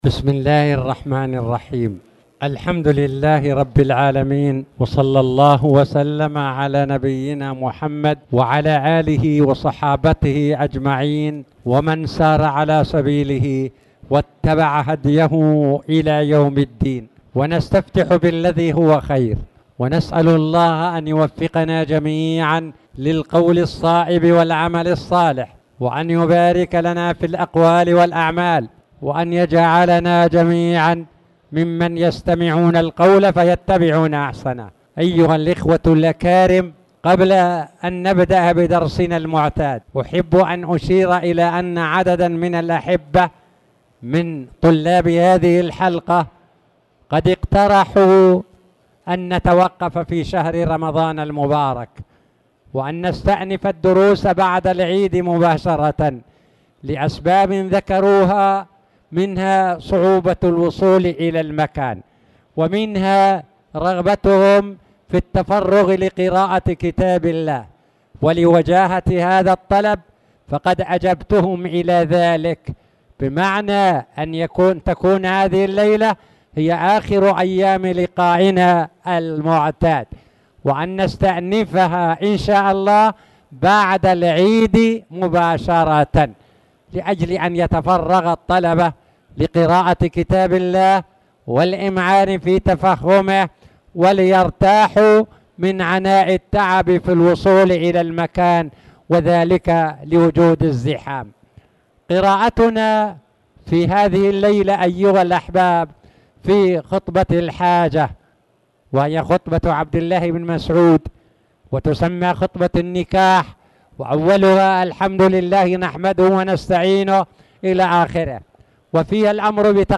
تاريخ النشر ٢٩ شعبان ١٤٣٨ هـ المكان: المسجد الحرام الشيخ